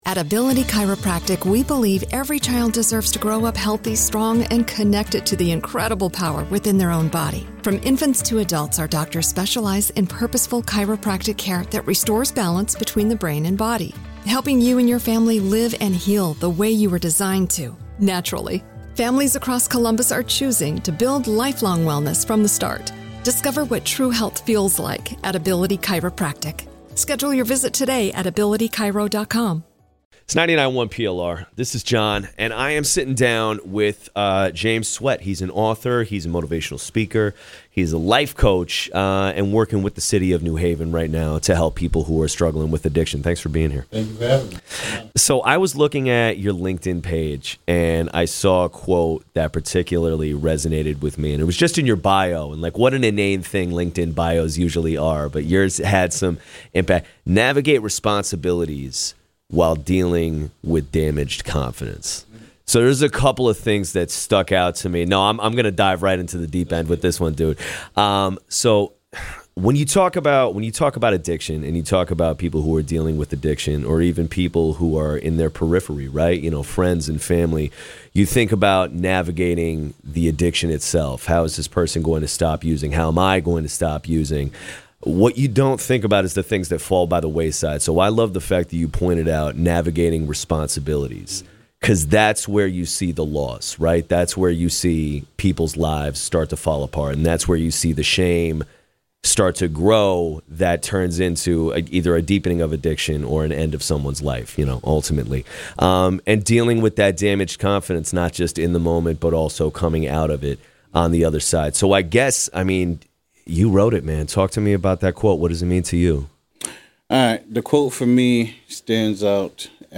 Interviewed live on KVLF